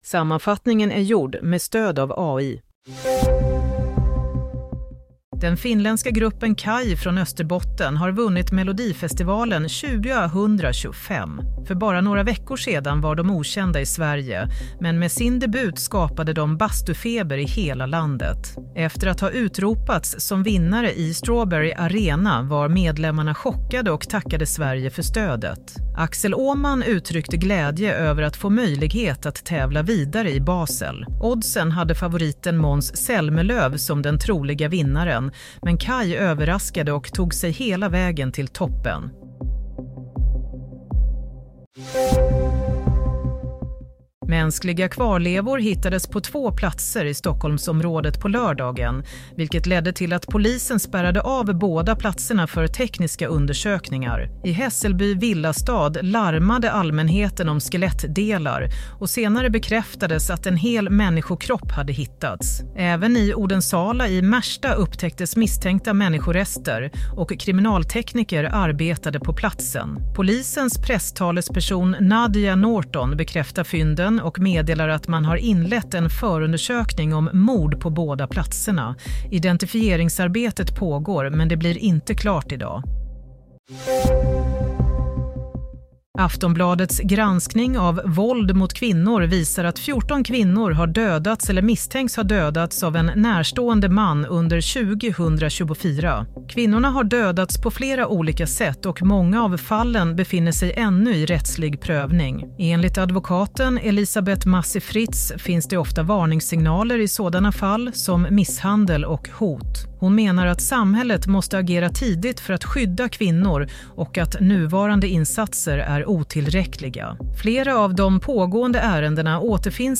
Play - Nyhetssammanfattning – 8 mars 22:00
Sammanfattningen av följande nyheter är gjord med stöd av AI. - KAJ vinner Melodifestivalen 2025 - Mänskliga kvarlevor hittade på två platser i Stockholm - 371 kvinnor dödade av en man de älskat Broadcast on: 08 Mar 2025